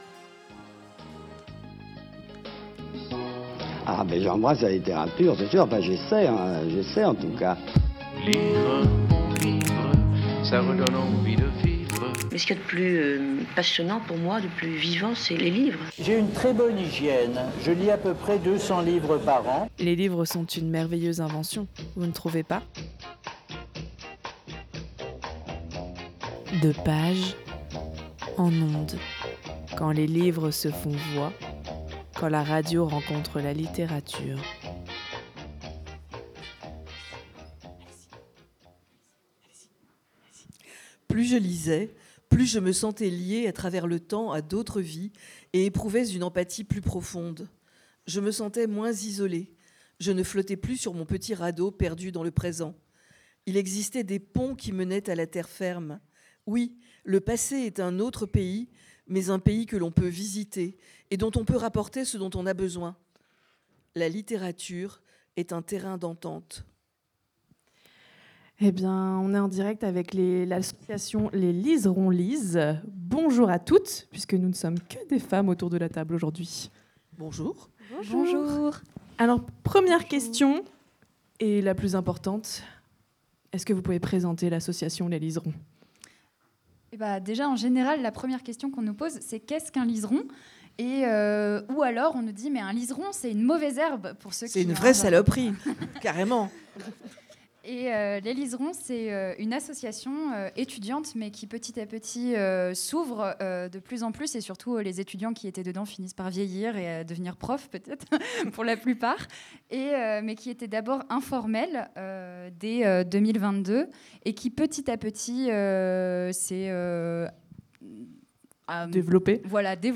Samedi 22 mars, les animateurs et animatrices Radio Campus sur scène au Chaudron - Scène étudiante du Crous et en direct !
L’association Des Liserons lisent nous fait vivre en direct une émission De pages en ondes.